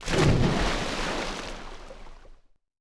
splashOthers.ogg